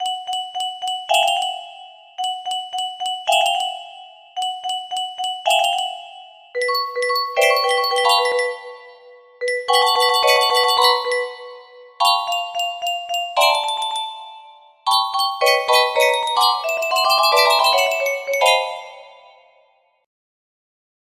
10207 music box melody
Grand Illusions 30 (F scale)